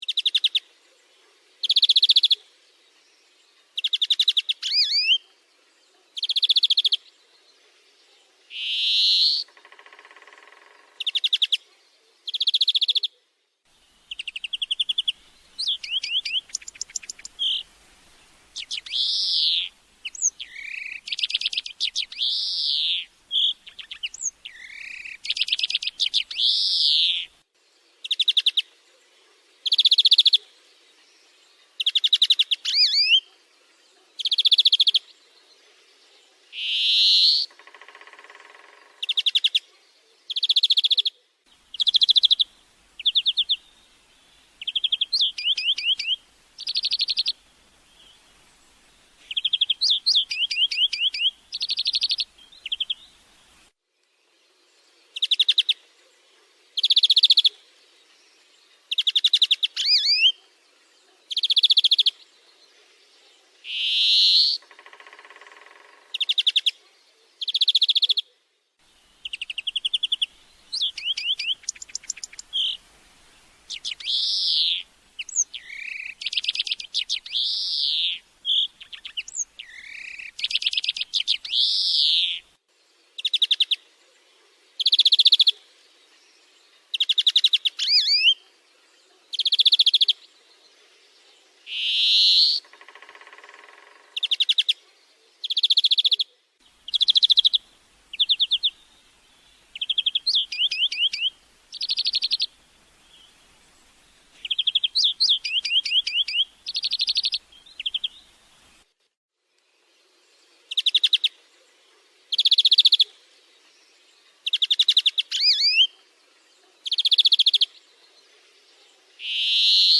Florya Kuşunun Ötüşü Nasıldır?
Florya-Otusu.mp3